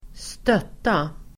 Uttal: [²st'öt:a]